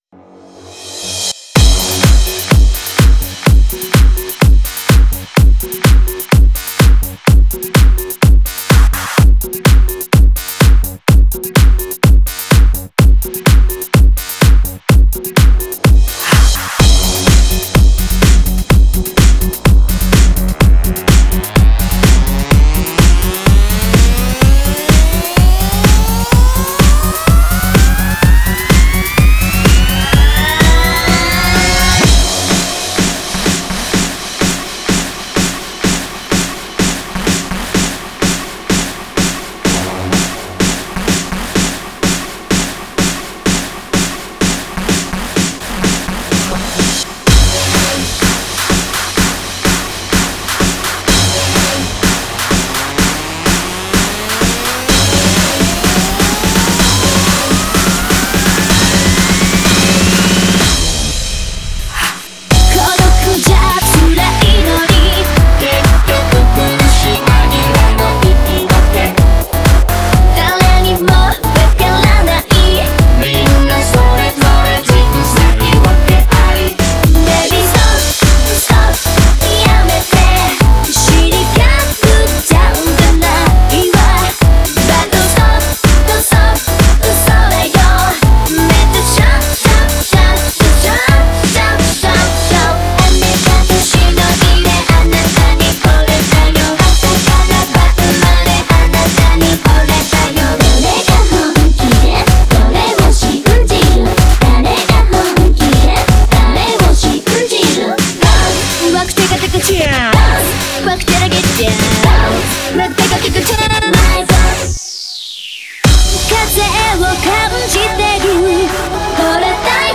Genre(s): Electro-House